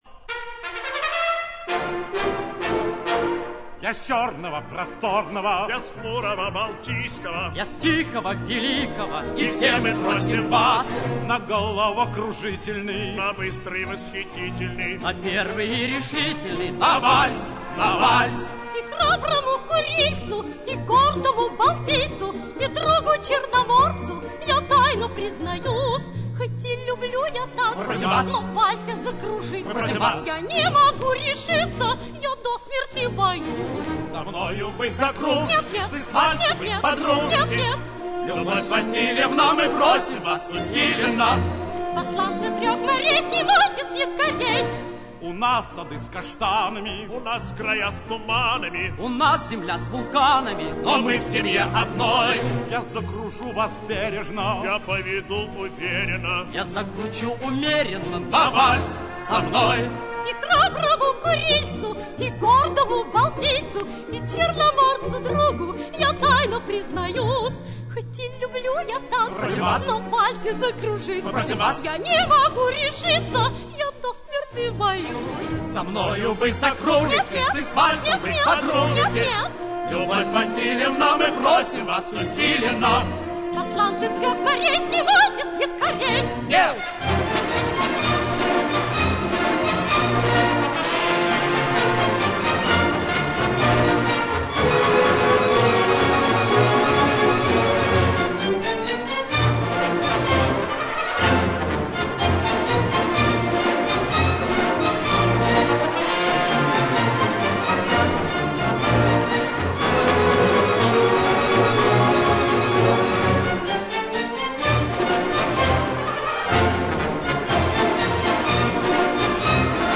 звук оп-ты 1961